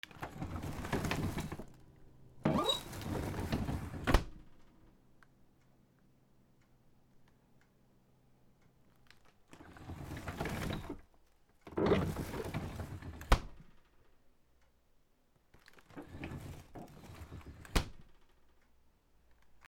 冷蔵庫 野菜ボックス 時計音あり